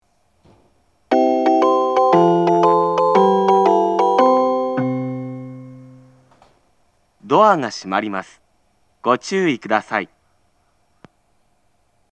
■社家駅　ホームの様子（門沢橋方から撮影）
発車メロディー
一度扱えばフルコーラス鳴ります。